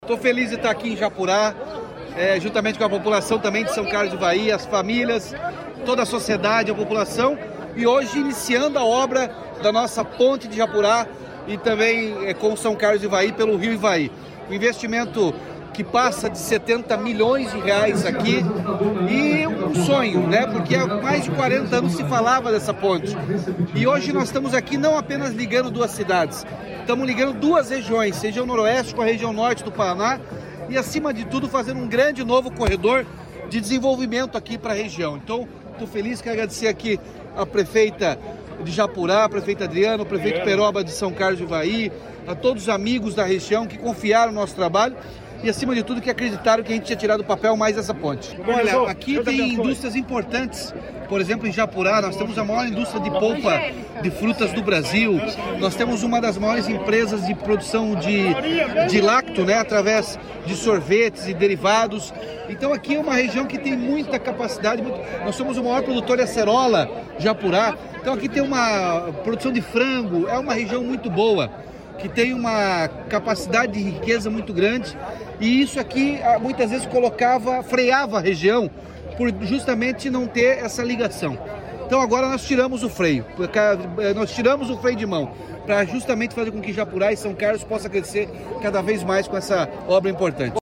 Sonora do governador Ratinho Junior sobre obra da ponte entre Japurá e São Carlos do Ivaí